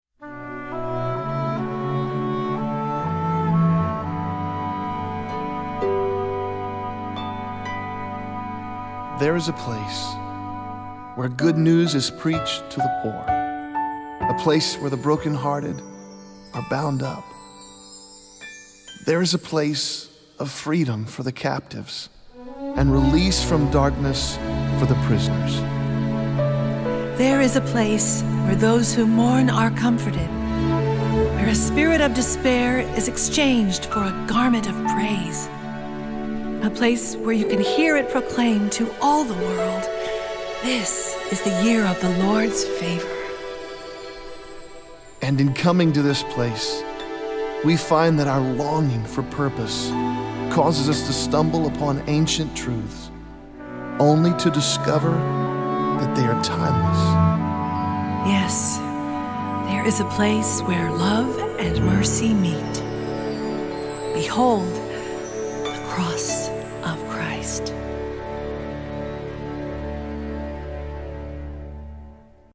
Voicing: S/a/t/b - Rehearsal